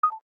cam_stop.ogg